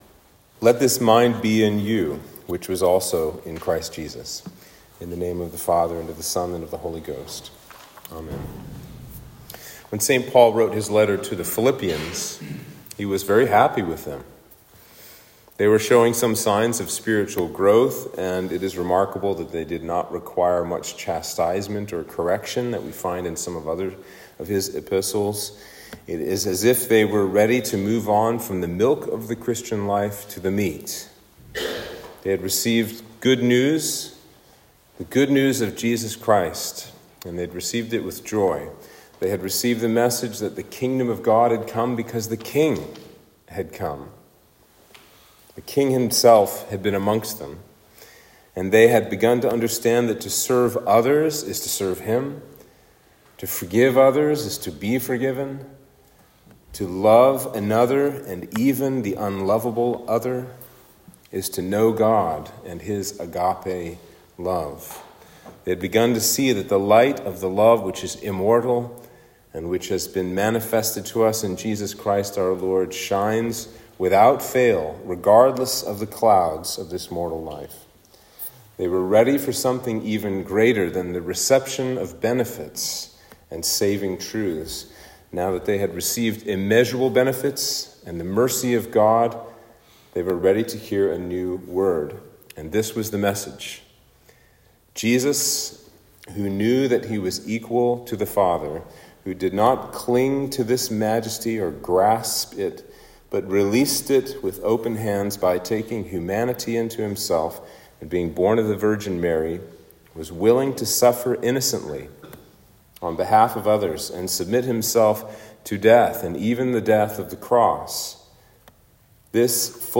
Sermon for Palm Sunday